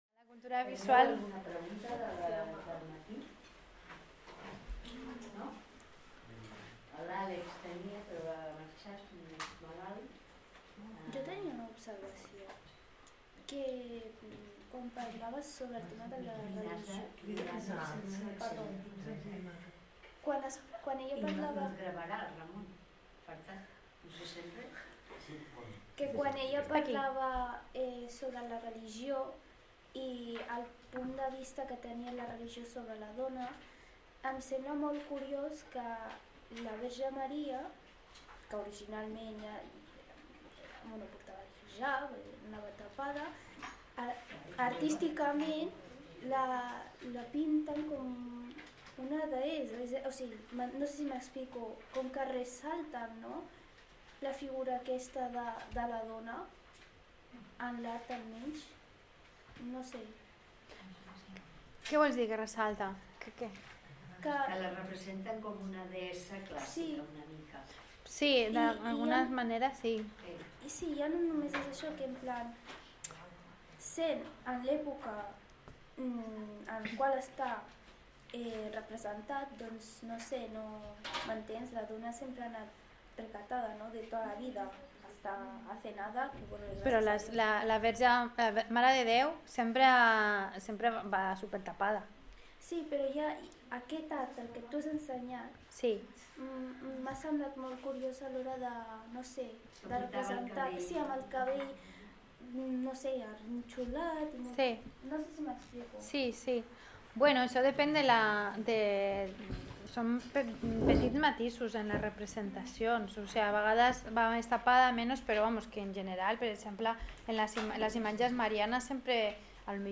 presenta la conferència